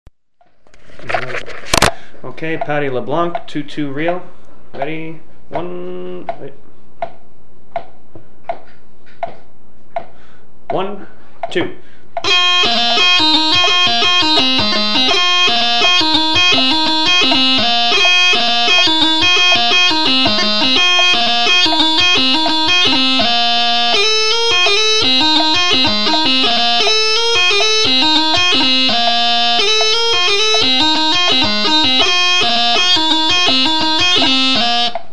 Paddy LeBlanc on practice chanter (mp3)